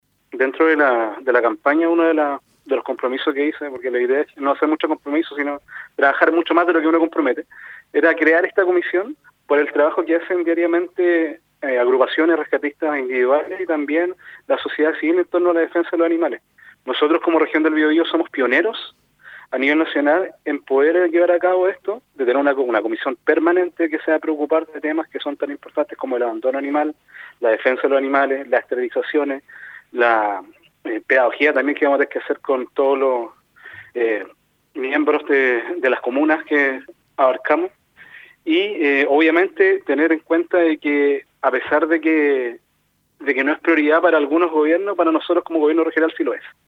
En entrevista con Radio UdeC, destacó el carácter pionero a nivel nacional que tendrá esta iniciativa.